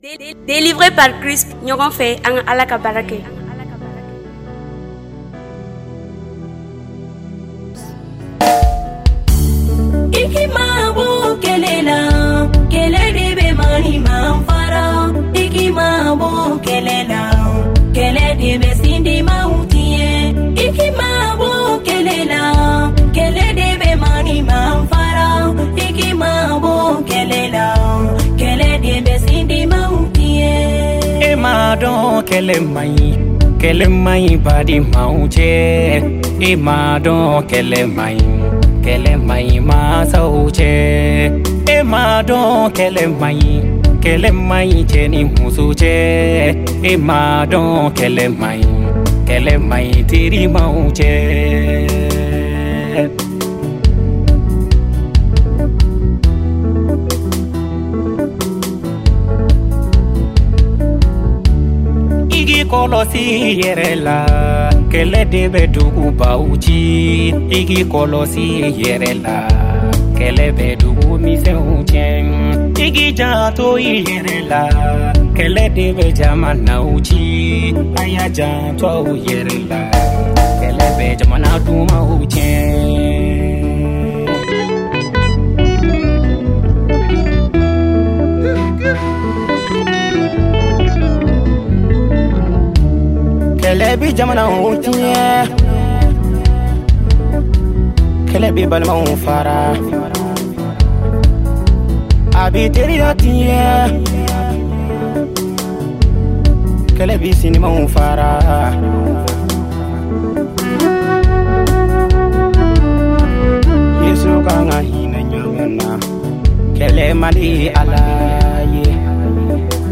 chantre